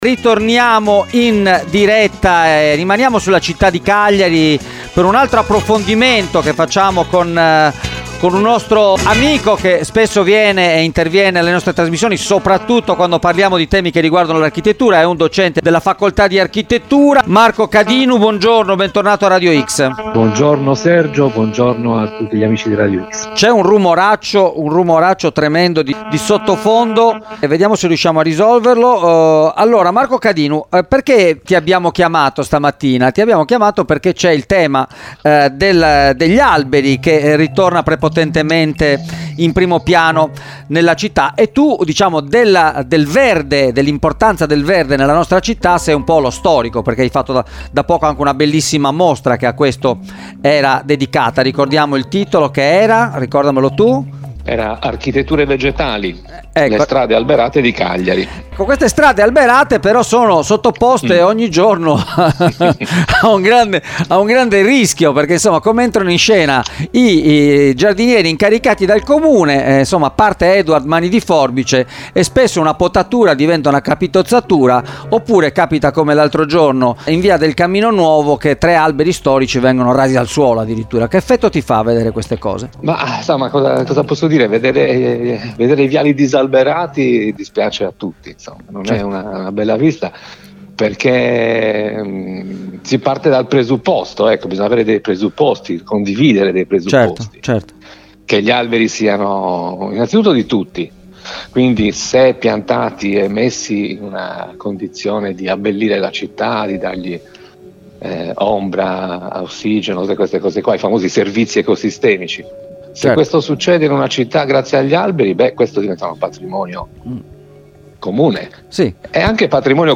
Tutela verde pubblico, intervista